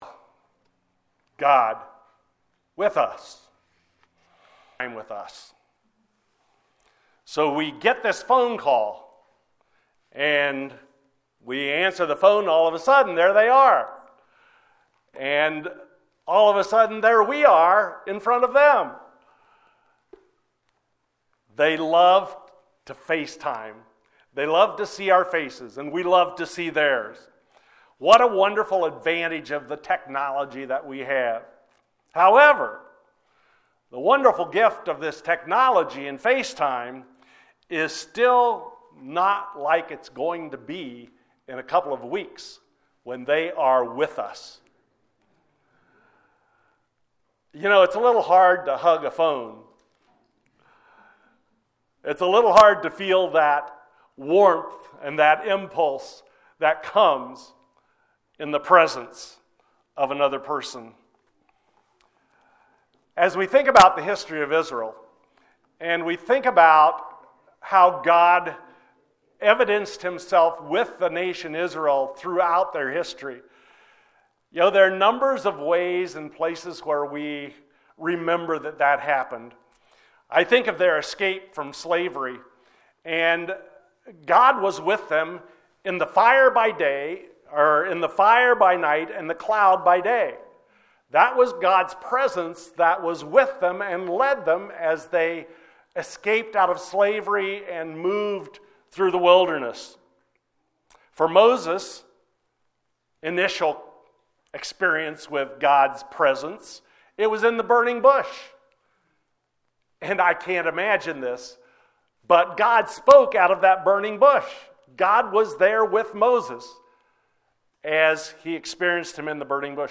wp-content/uploads/2021/12/Immanuel-God-with-us.mp3 A sermon from Matthew 1:20-23.